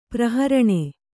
♪ praharaṇe